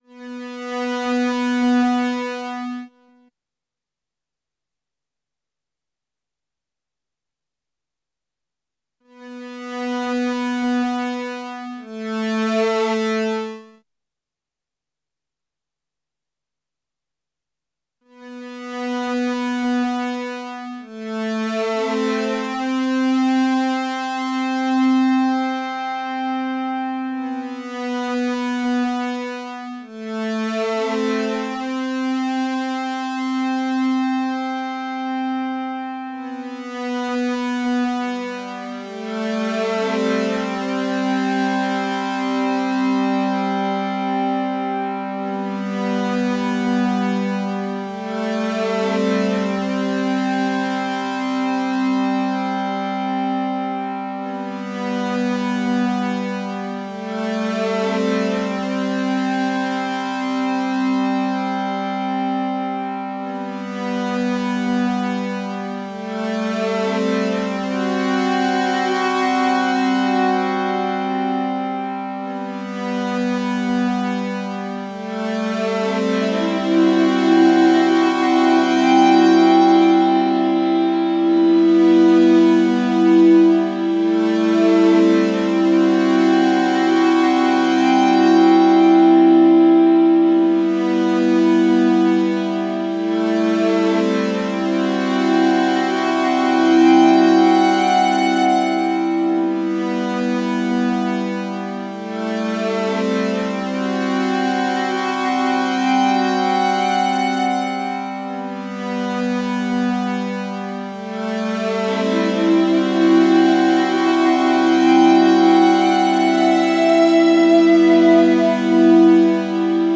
guitar and other sounds
saxophone